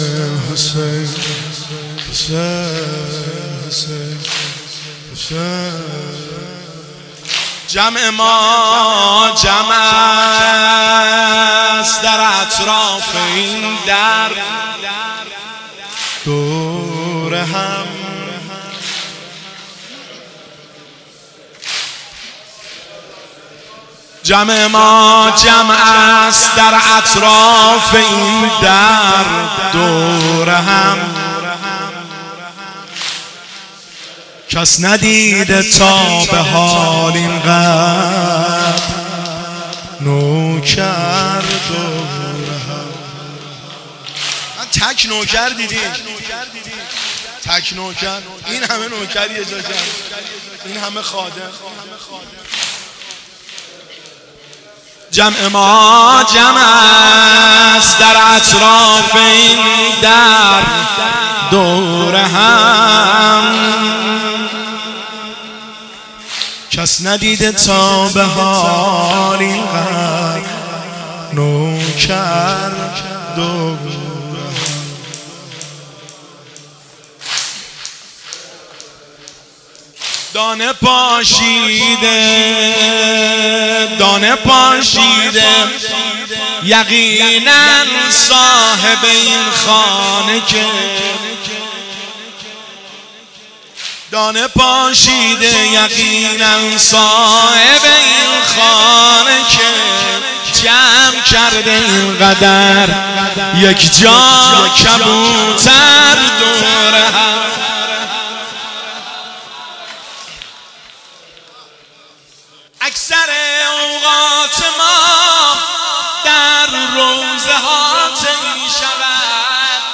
هیئت بین الحرمین طهران
جمع-ما-جمع-است-شعرخوانی.mp3